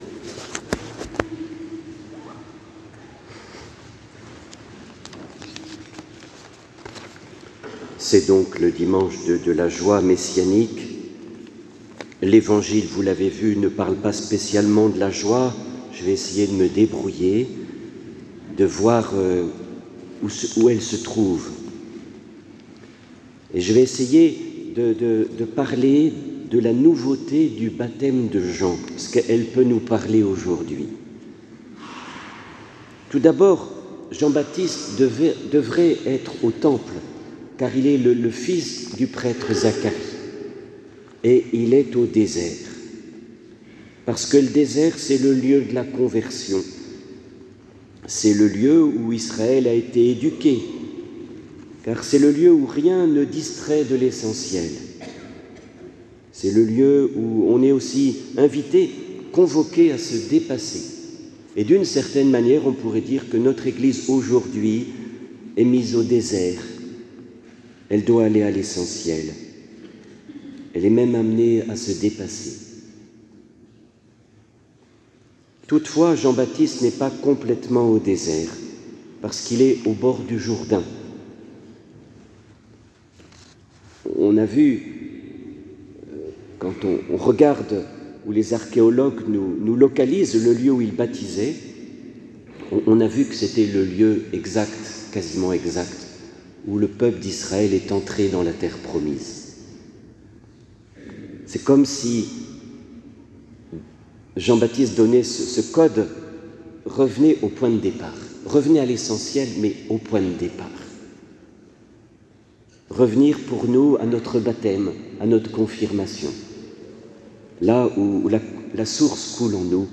Homélie et PU du 3e dimanche de l’Avent : la nouveauté de Jean le Baptiste